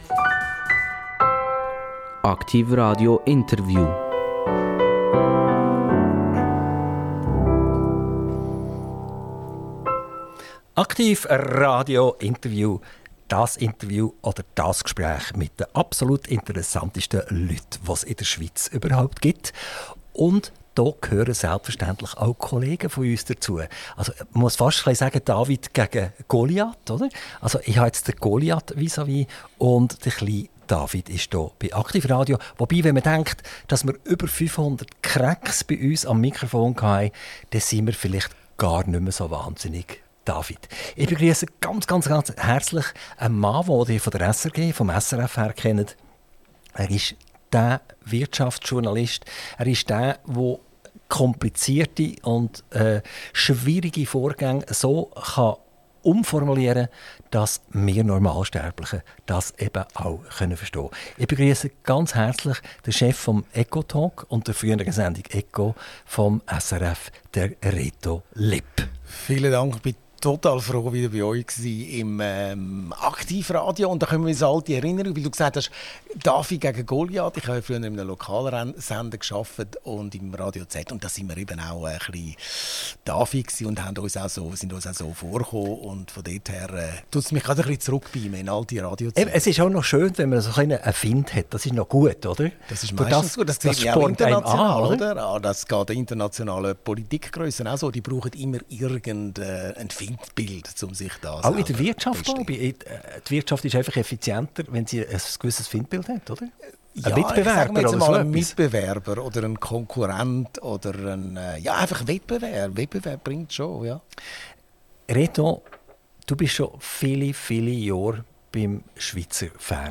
INTERVIEW - Reto Lipp - 18.04.2024